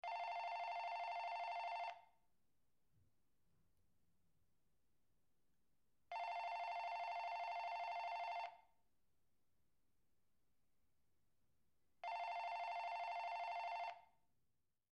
ringincoming.mp3